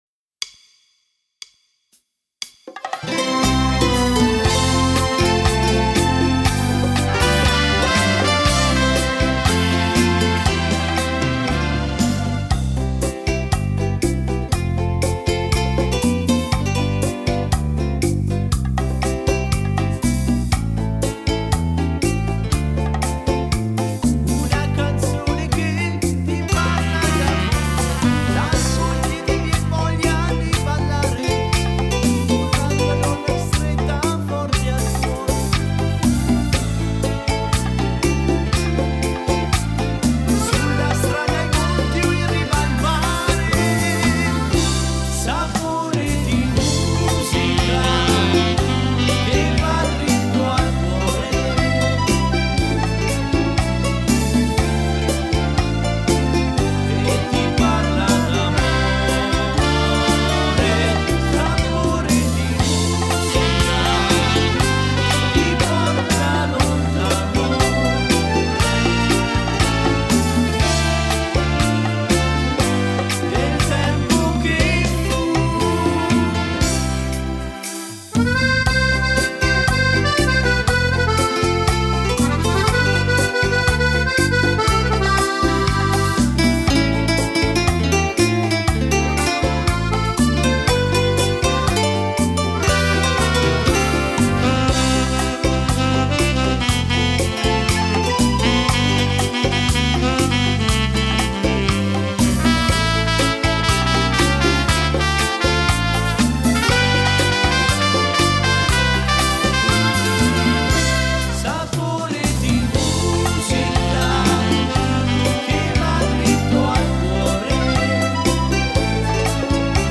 Beguine bachata
Uomo